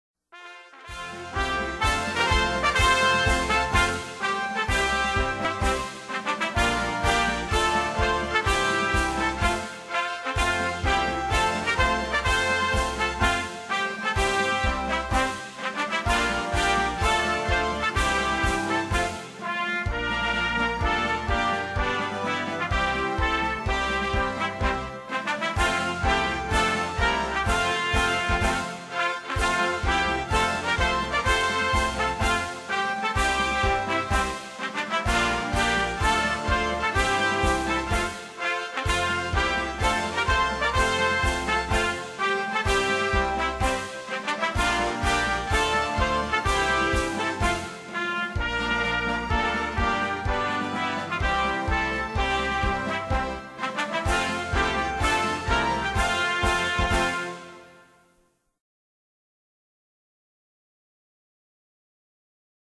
音樂